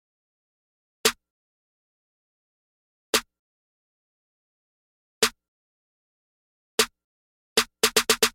最好的免费恐怖声音 " 嗯，很高兴